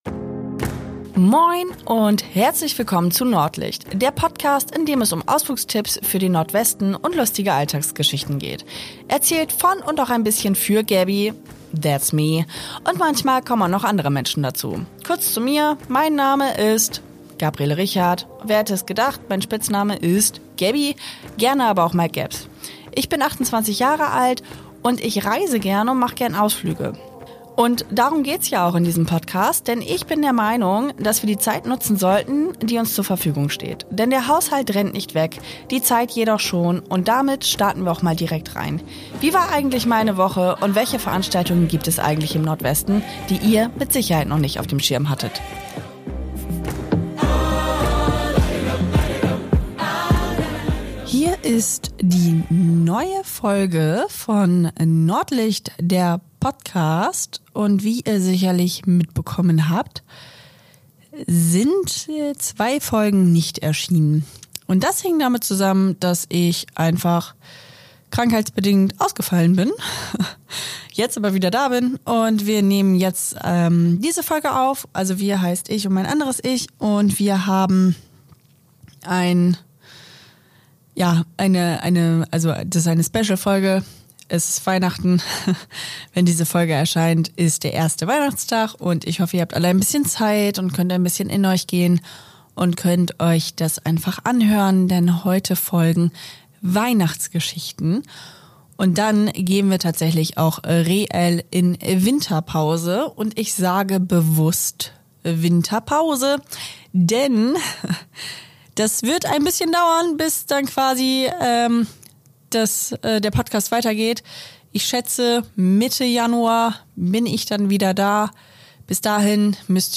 In dieser Folge erzähle ich euch wieder eine lustige Anekdote. Des Weiteren lese ich euch Weihnachtsgeschichten vor um euch in besinnliche Weihnachtsstimmung zu bringen.